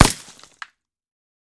main Divergent / mods / JSRS Sound Mod / gamedata / sounds / material / bullet / collide / tree01gr.ogg 36 KiB (Stored with Git LFS) Raw Permalink History